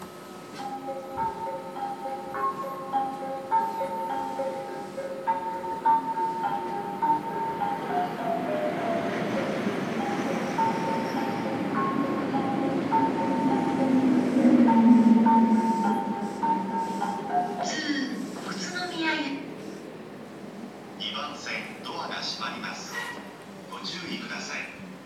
久喜駅　Kuki Station ◆スピーカー：小VOSS,ユニペックス箱型
2番線発車メロディー